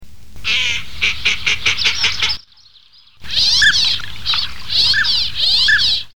Canards pilets